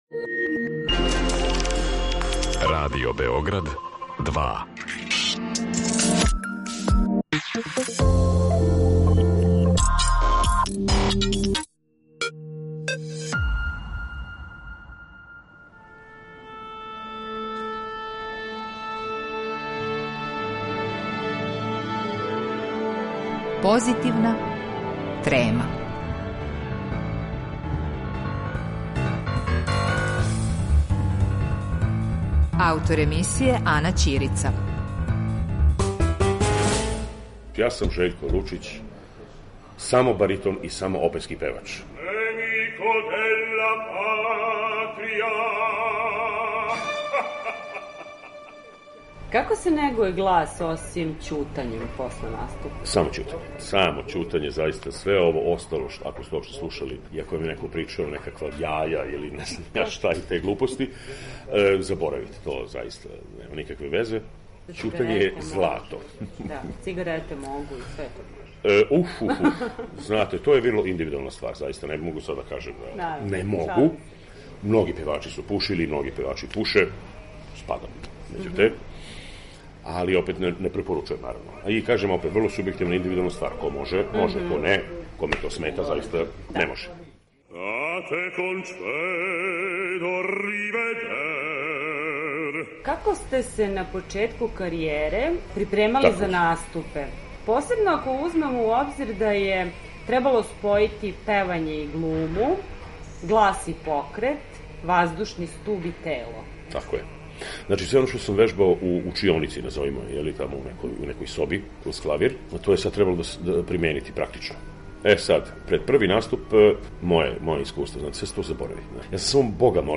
Овога пута, Жељко Лучић нам говори о гласу, припреми за наступе, треми, односу према ликовима које је тумачио, као и о најважнијим животним сазнањима до којих је дошао градећи плодну каријеру.